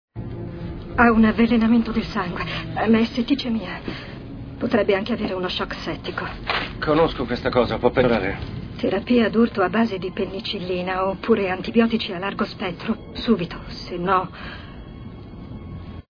dal film "The Day After Tomorrow - L'alba del giorno dopo", in cui doppia Sheila McCarthy.